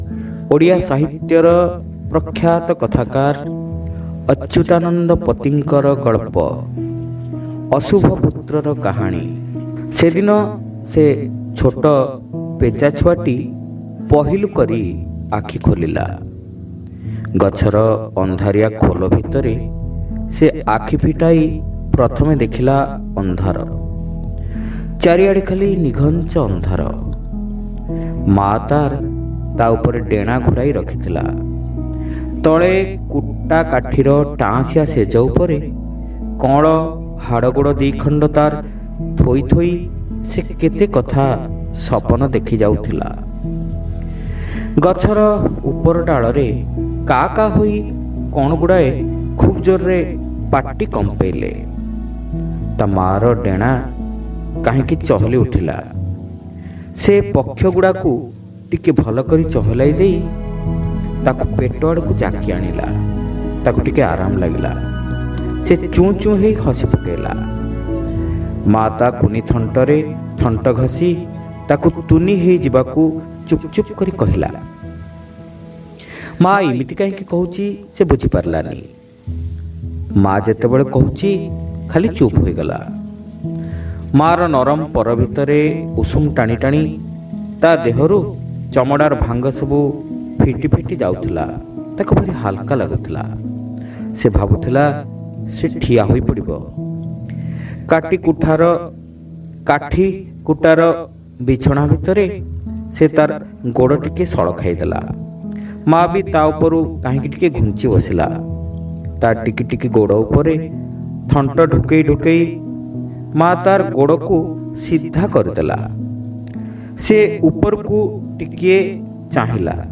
ଶ୍ରାବ୍ୟ ଗଳ୍ପ : ଅଶୁଭ ପୁତ୍ରର କାହାଣୀ (ପ୍ରଥମ ଭାଗ)